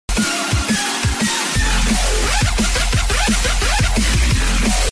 old-donation-gift-sound.mp3